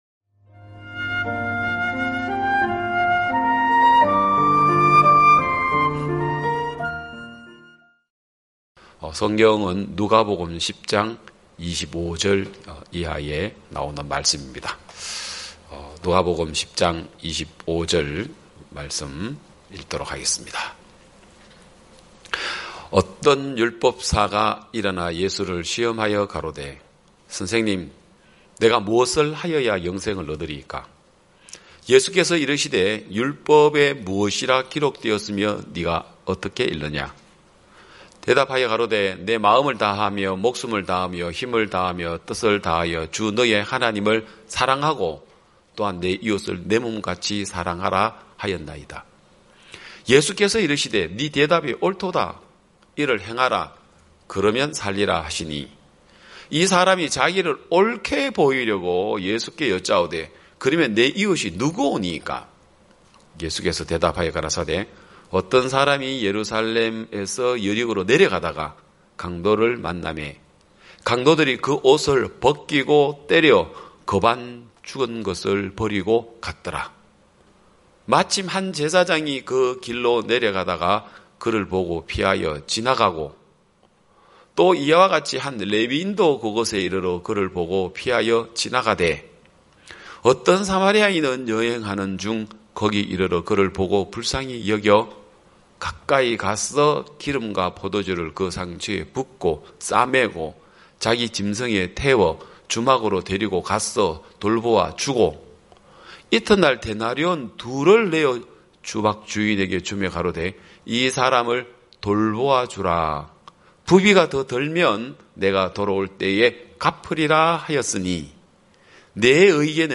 2021년 6월 13일 기쁜소식양천교회 주일오전예배
성도들이 모두 교회에 모여 말씀을 듣는 주일 예배의 설교는, 한 주간 우리 마음을 채웠던 생각을 내려두고 하나님의 말씀으로 가득 채우는 시간입니다.